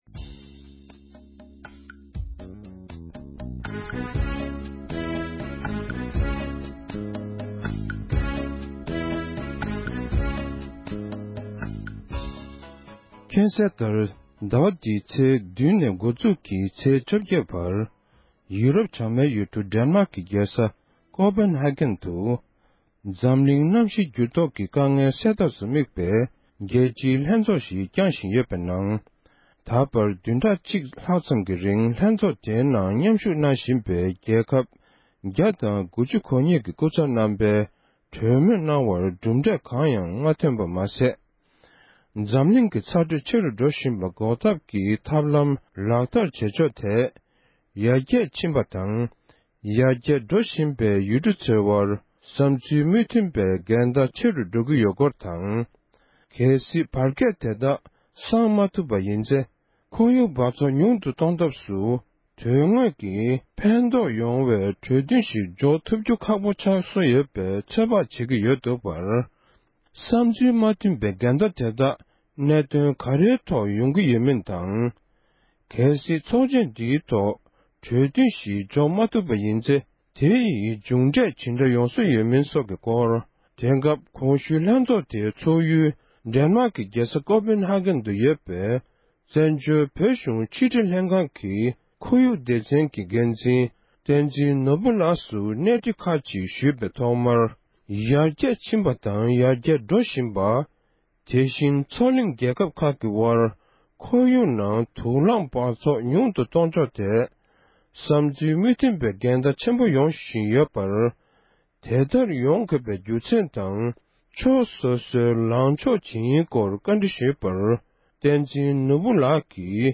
འབྲེལ་ཡོད་གནས་ཚུལ་བཀའ་འདྲི་ཞུས་པར་གསན་རོགས༎